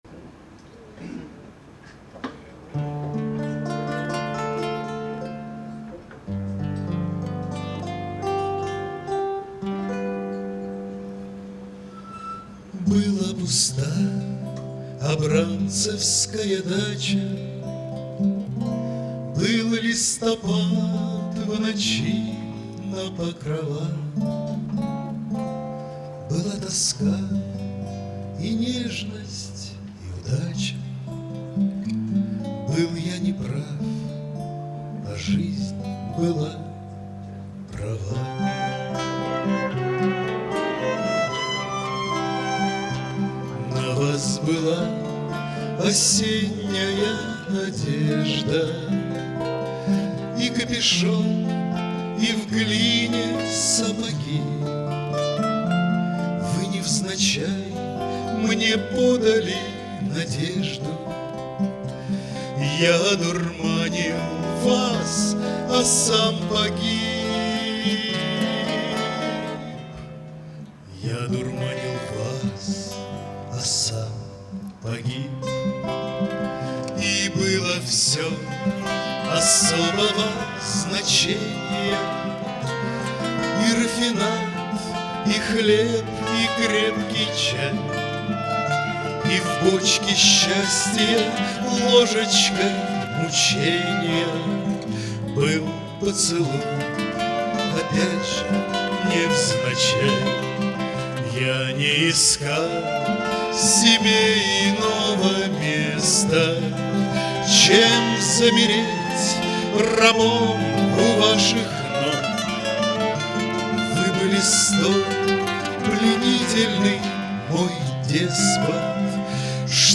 Клуб "Альма матер". 26.03.2011